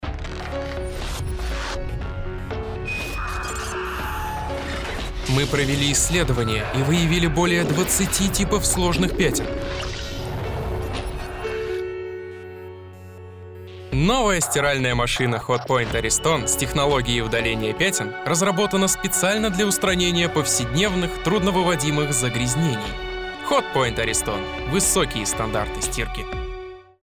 Владею широким диапазоном эмоций - все для вас, как говорится.
Blue Yeti